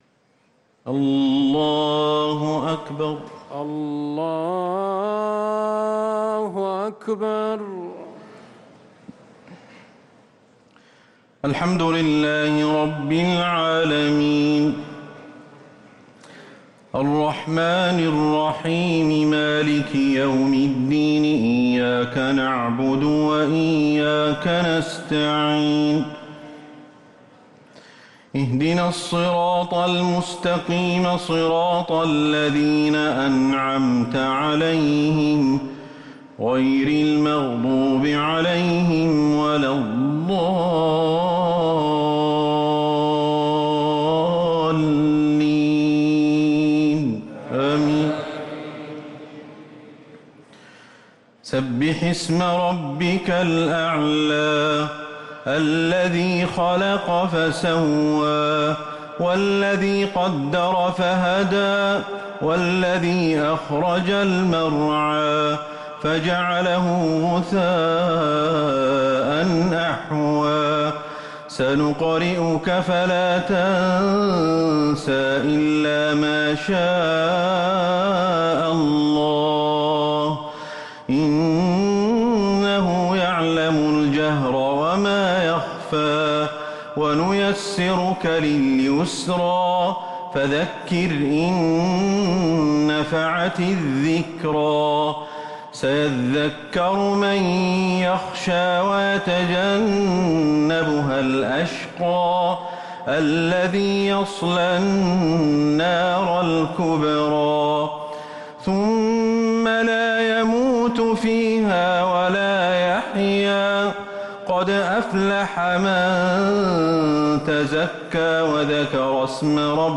صلاة الشفع والوتر مع دعاء القنوت ليلة 21 رمضان 1444هـ > رمضان 1444هـ > التراويح - تلاوات الشيخ أحمد الحذيفي